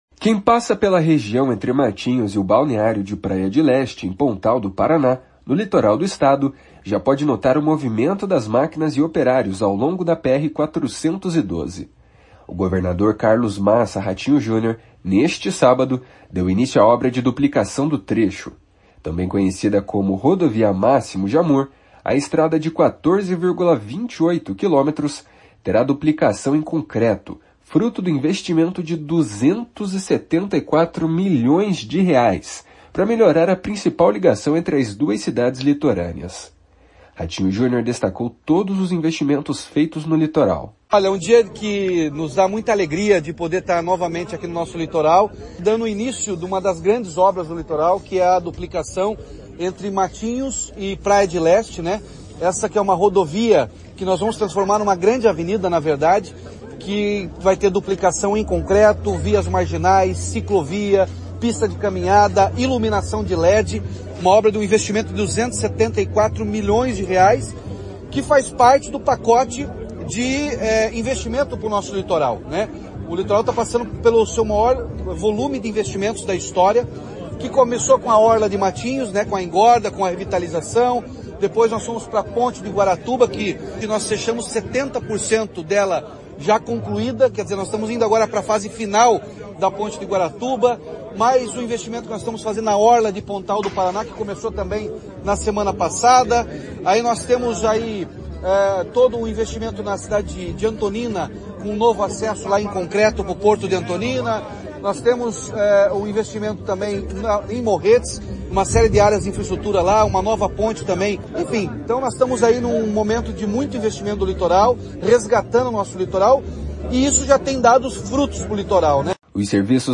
// SONORA RATINHO JUNIOR //
// SONORA RUDÃO GIMENEZ //